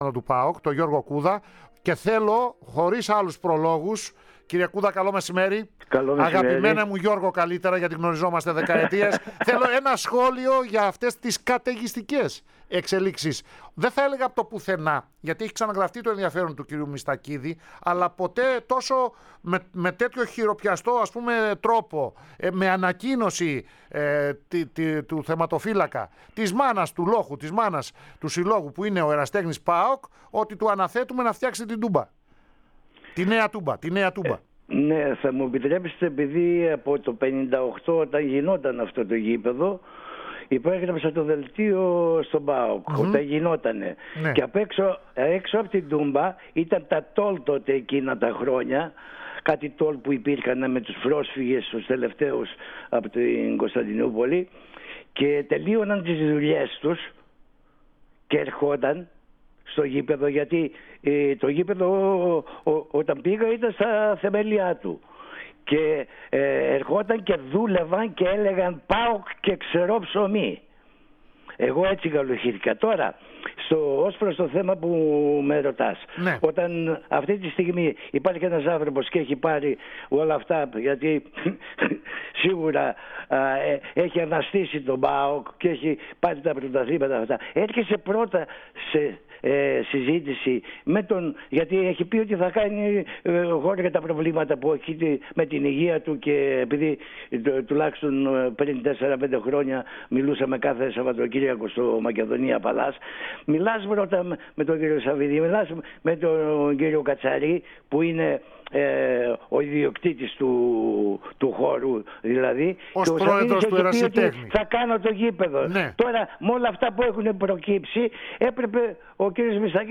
Ο Γιώργος Κούδας φιλοξενήθηκε στην εκπομπή της ΕΡΑ ΣΠΟΡ "Κατά Ιωάννου Ευαγγέλιο" και μίλησε για το ζήτημα που απασχολεί την ασπρόμαυρη επικαιρότητα σχετικά με τη Νέα Τούμπα.